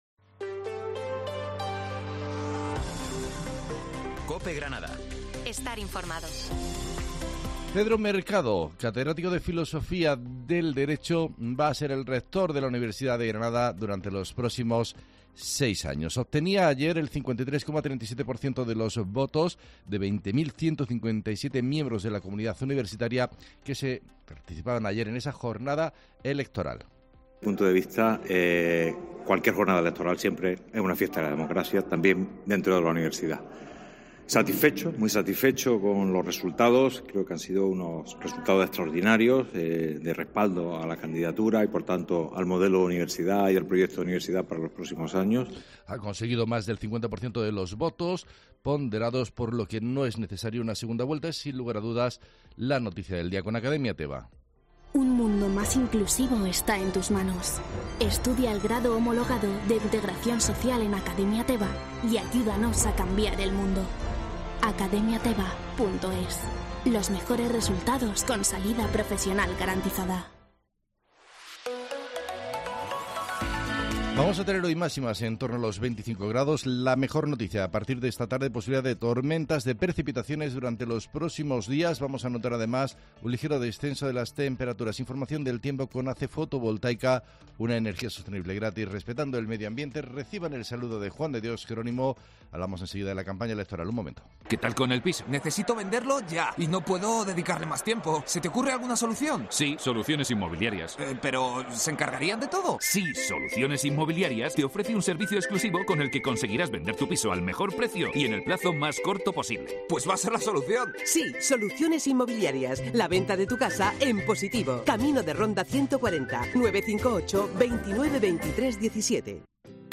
Herrera en COPE Granada. Informativo 17 de mayo de 2023